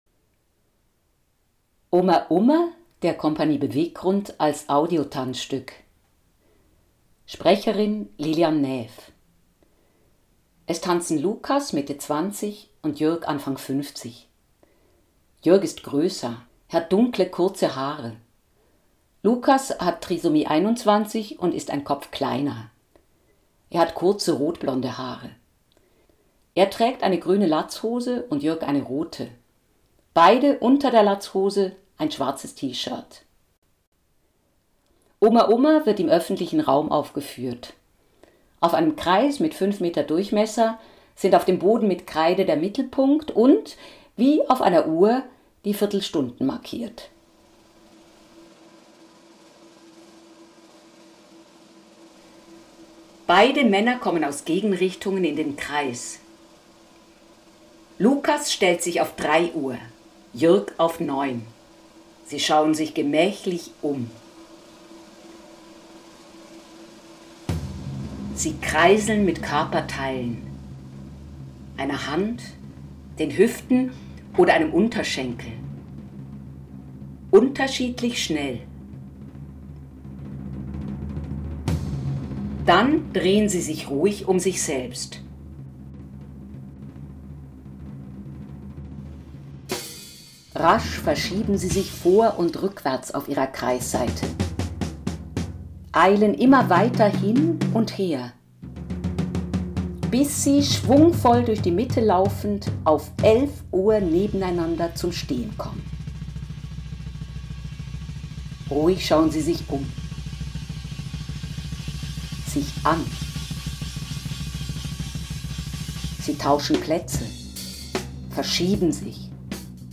Audiostück